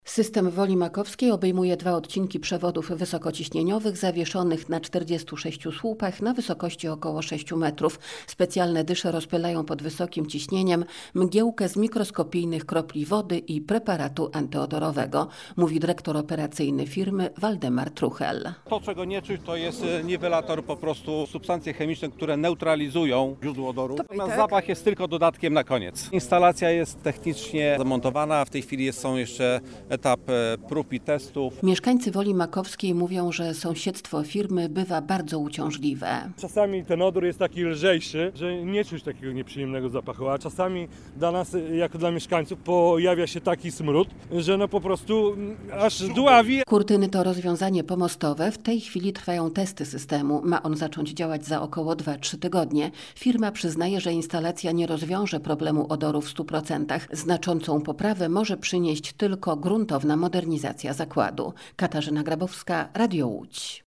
– Czasami odór jest lżejszy, a czasami pojawia się taki smród, że aż dławi – mówi jeden z mieszkańców.